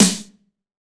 ESNARE 064.wav